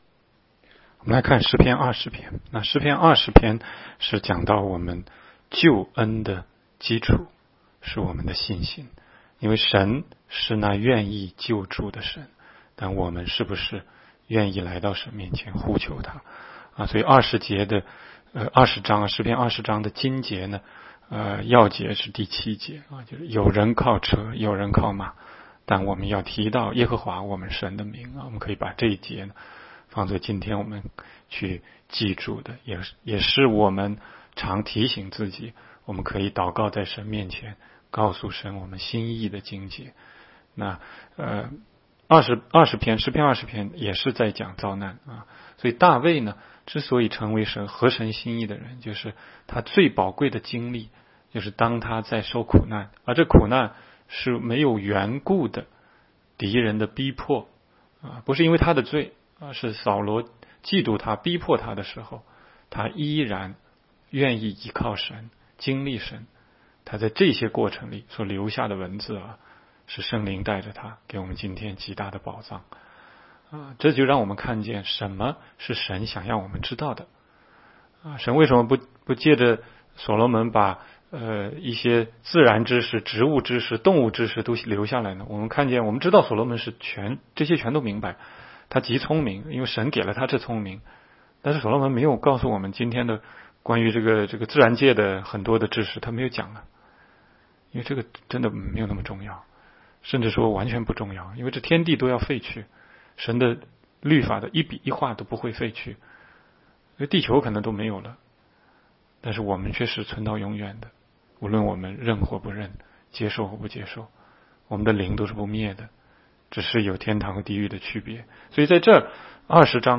16街讲道录音 - 每日读经-《诗篇》20章